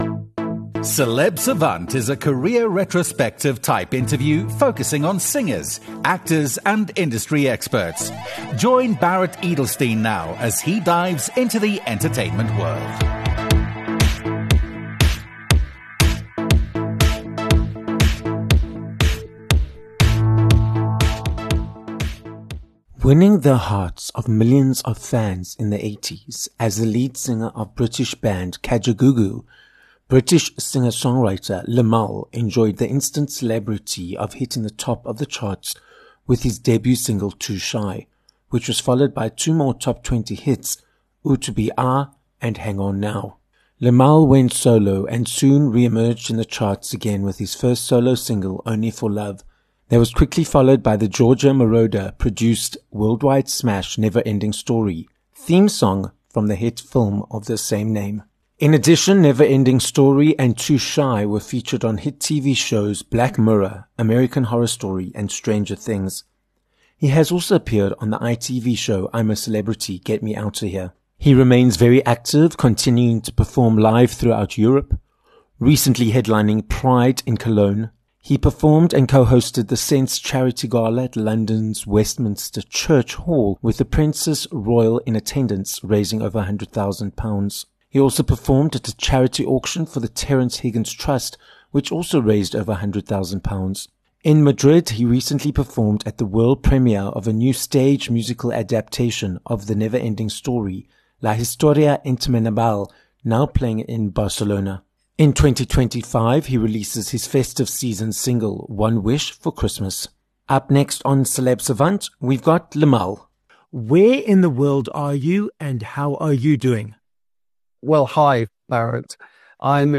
Limahl - a British singer and songwriter - joins us on this episode of Celeb Savant. Limahl takes us through his multi-decade career, which includes reaching the top of the charts in the band Kajagoogoo with their first song Too Shy, his experience of being used as clickbait, and what the effects are of having his music featured in current series such as Stranger Things.